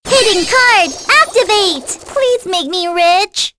May-Vox_Skill4.wav